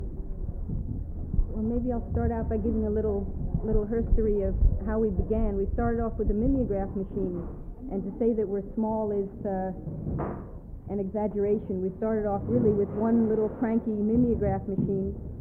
The panelists describe the founding of the Collective in Oakland, California.
1/4 inch audio tape